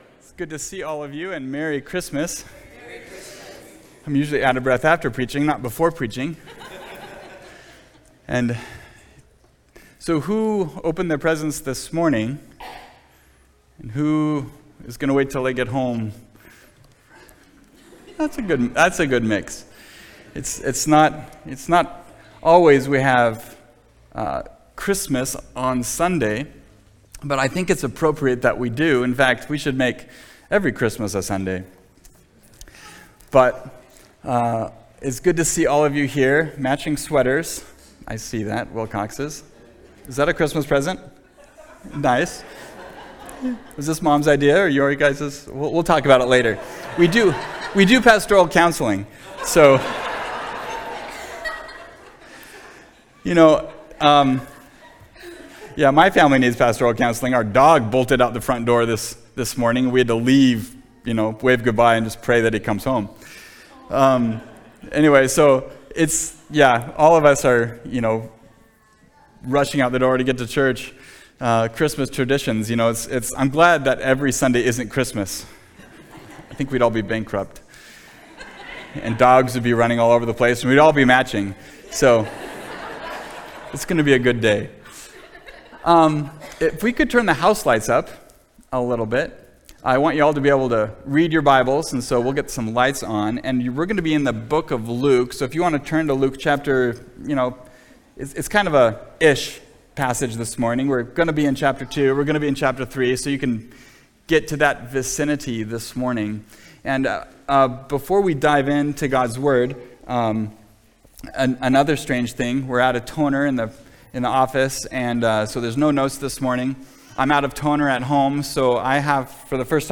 Christmas Message